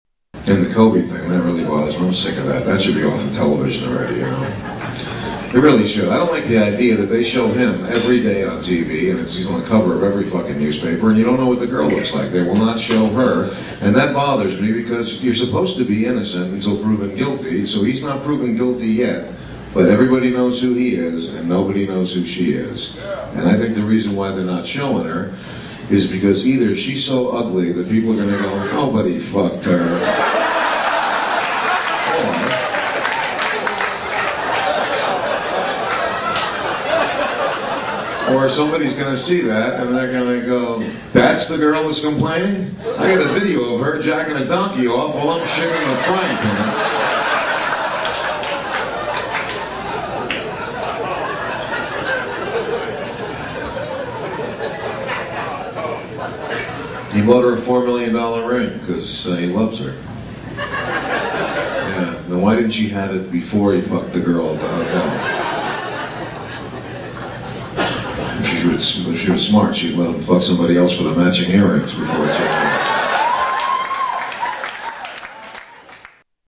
Category: Comedians   Right: Personal
Tags: Comedian Robert Schimmel clips Robert Schimmel audio Stand-up comedian Robert Schimmel